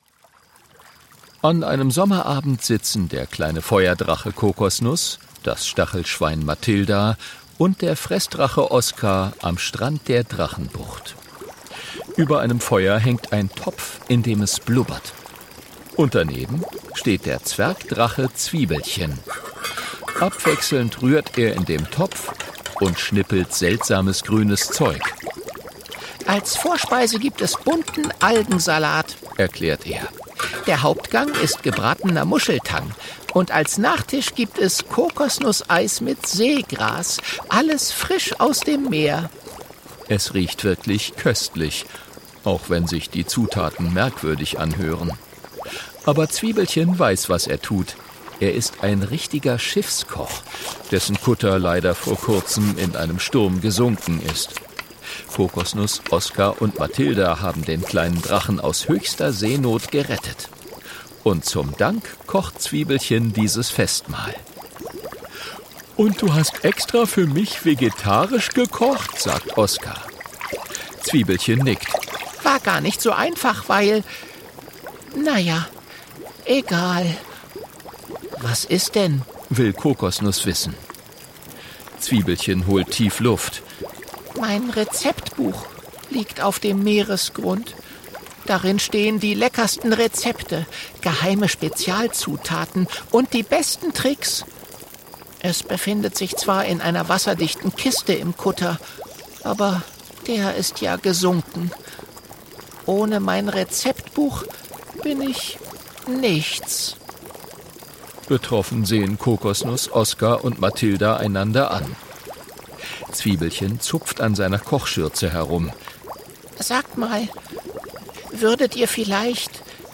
Hörbuch: Alles klar!